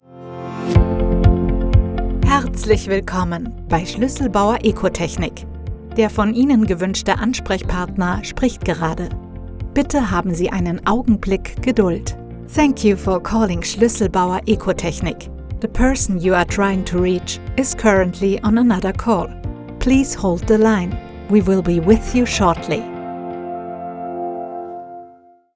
Telefonansage – Schlüsselbauer Ecotechnic
mitteltief ⋅ frisch ⋅ facettenreich